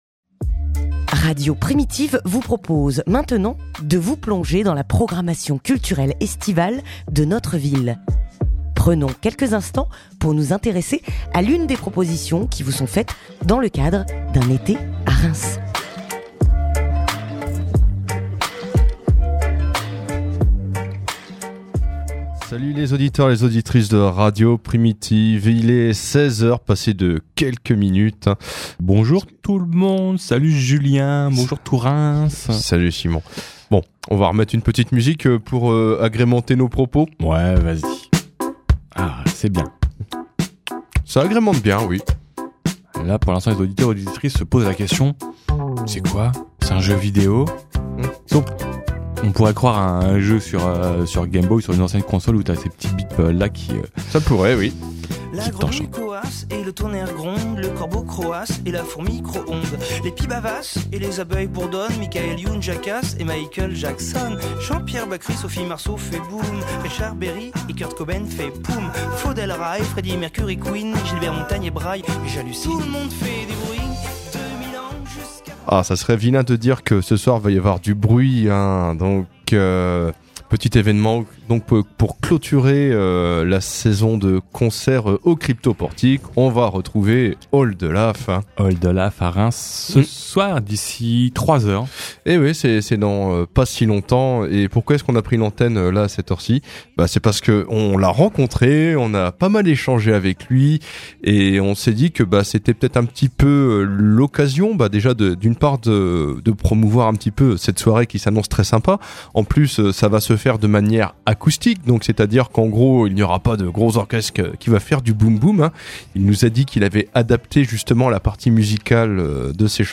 Interview d'Oldelaf (27:07)